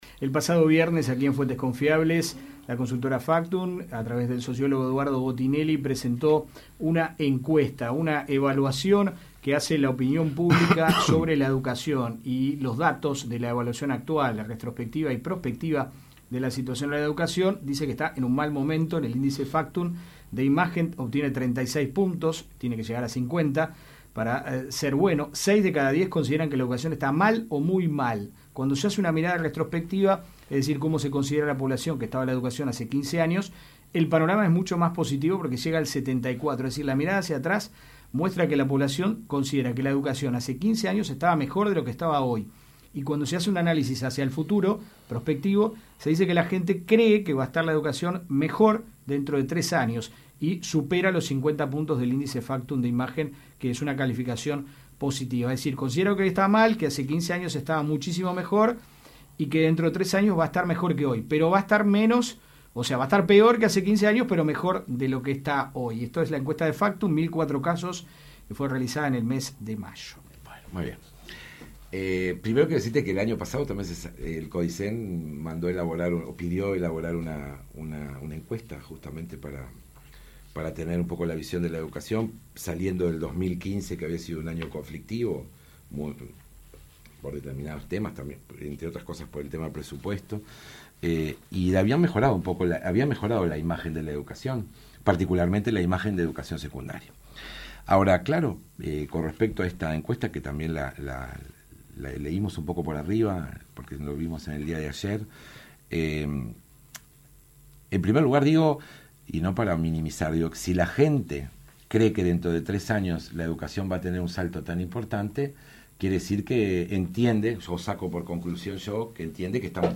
Javier Landoni, Consejero de Secundaria fue consultado sobre la encuesta de la consultora FACTUM en la opinión de la gente sobre la educación.